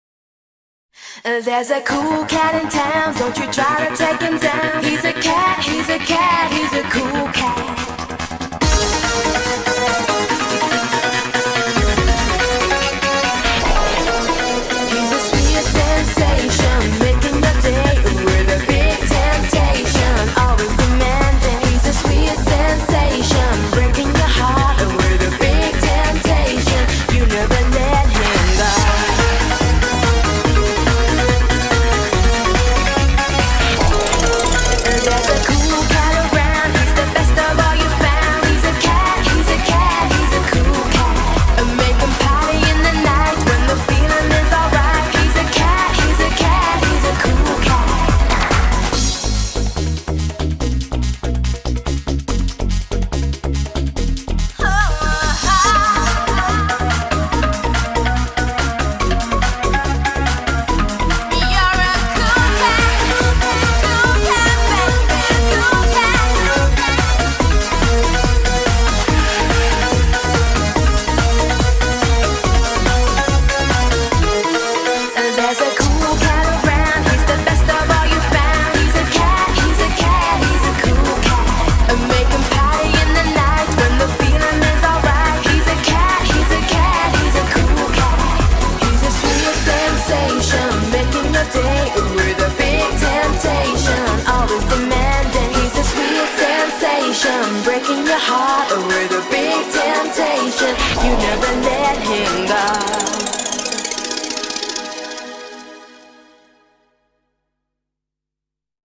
BPM143
EURODANCE